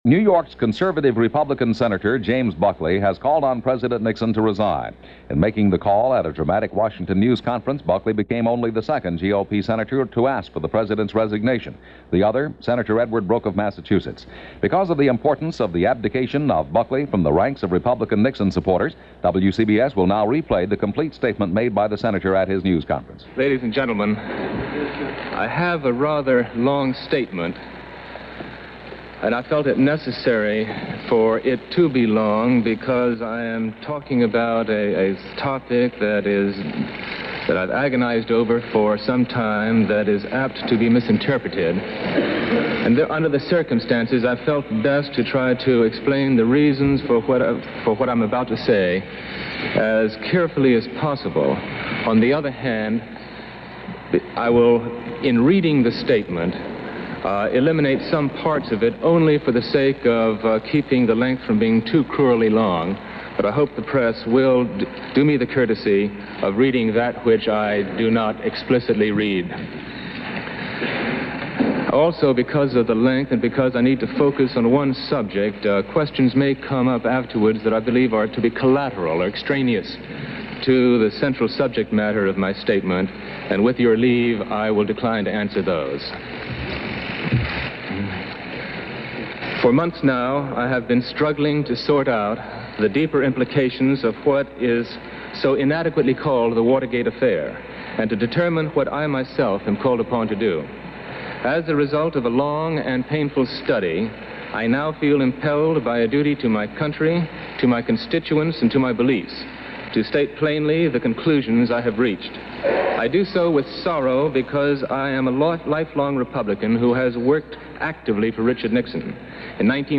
News Conference